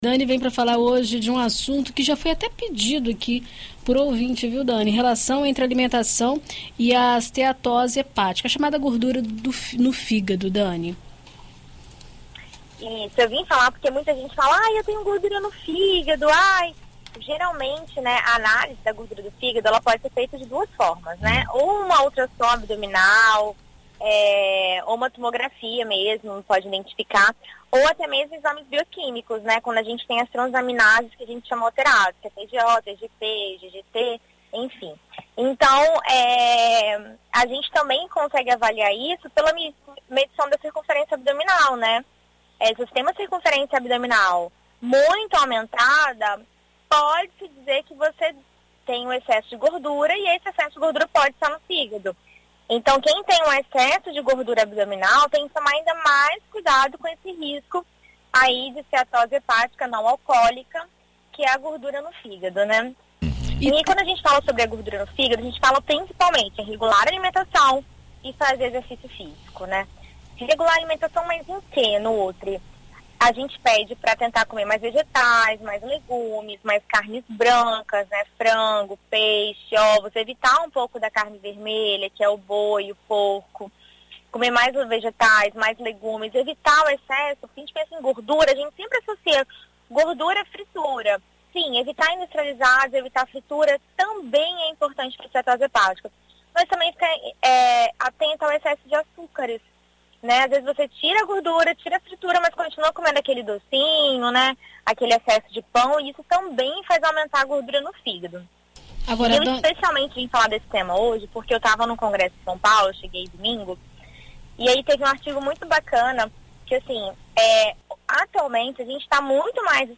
Na coluna Viver Bem desta quarta-feira (21), na BandNews FM Espírito Santo